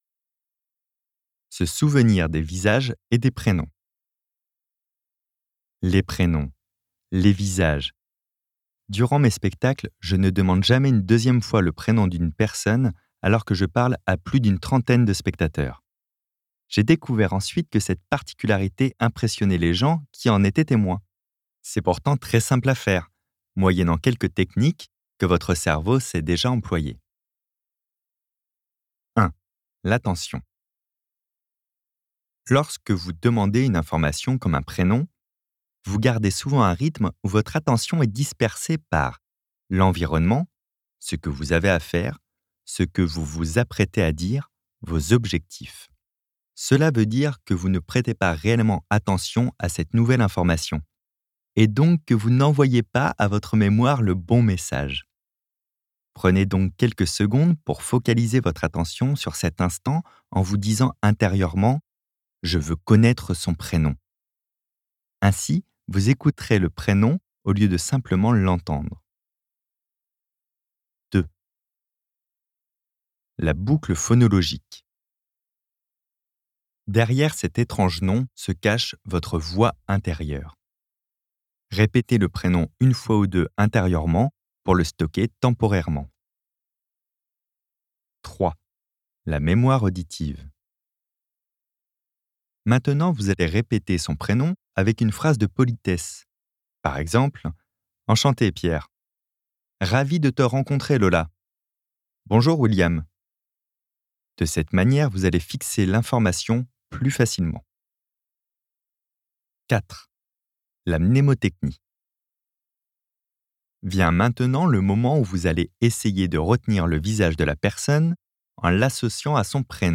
copyparty md/au/audiobook/Fabien Olicard - Votre cerveau est extraordinaire